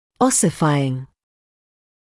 [‘ɔsɪfaɪŋ][‘осифайин]остеогенный, оссифицированный; оссифицирующийся